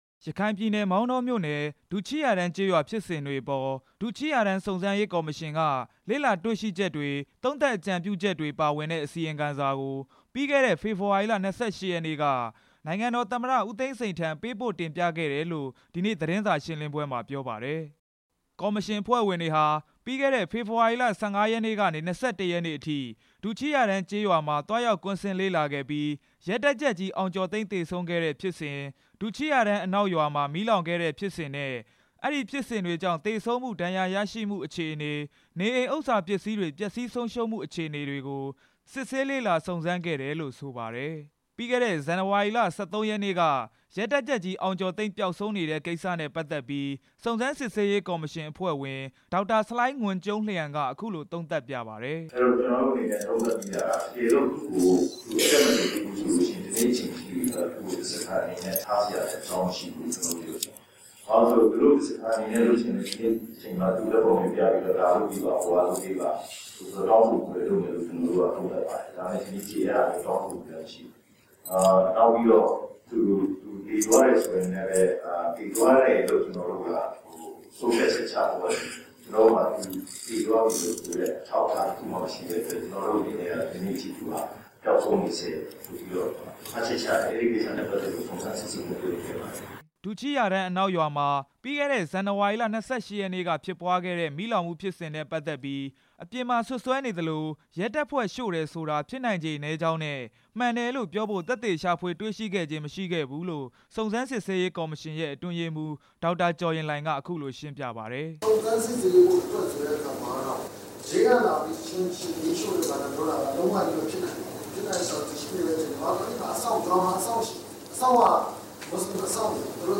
သတင်းစာရှင်းလင်းပွဲအကြောင်း တင်ပြချက်